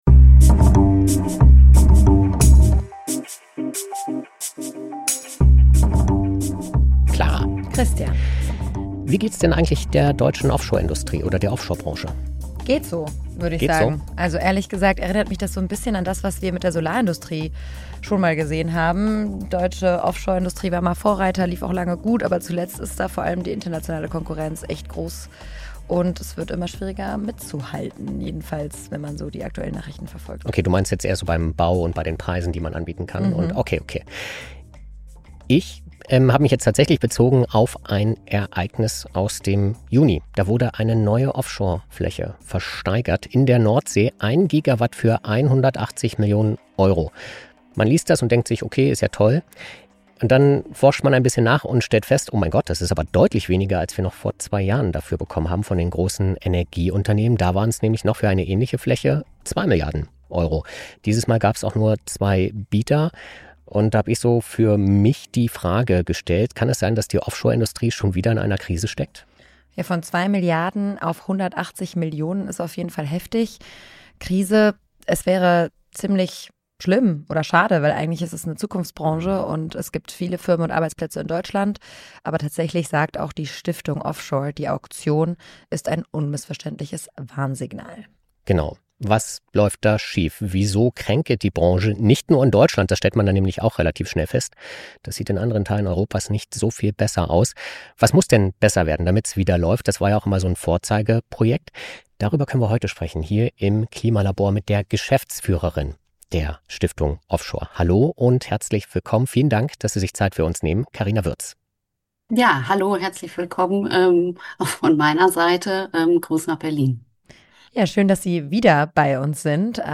Dann bewertet das "Klima-Labor" bei Apple Podcasts oder Spotify Das Interview als Text?